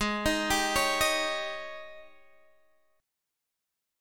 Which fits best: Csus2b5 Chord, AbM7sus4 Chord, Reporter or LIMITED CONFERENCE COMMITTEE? AbM7sus4 Chord